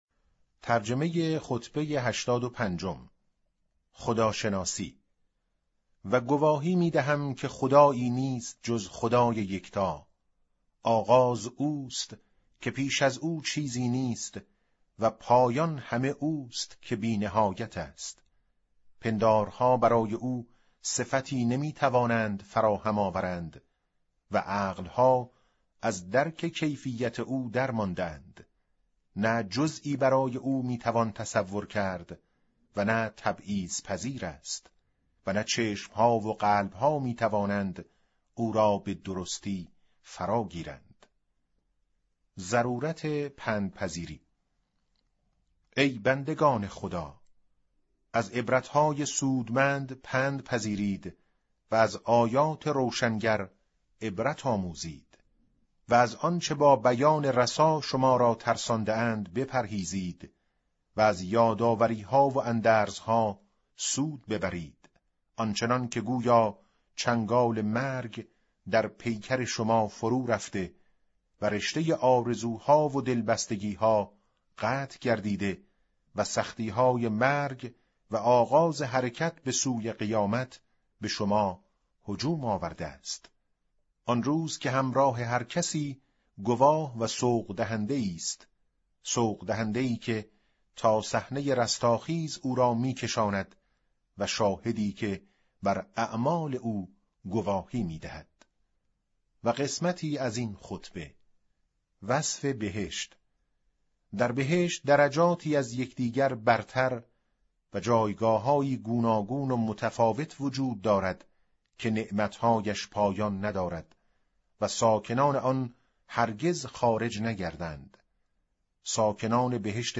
به گزارش وب گردی خبرگزاری صداوسیما؛ در این مطلب وب گردی قصد داریم، خطبه شماره ۸۵ از کتاب ارزشمند نهج البلاغه با ترجمه محمد دشتی را مرور نماییم، ضمنا صوت خوانش خطبه و ترجمه آن ضمیمه شده است: